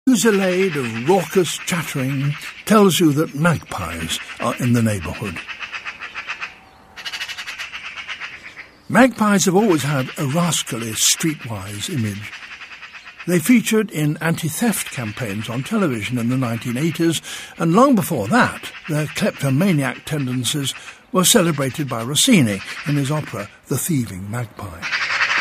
【英音模仿秀】晴来喜鹊无穷语，雨后寒花特地香 听力文件下载—在线英语听力室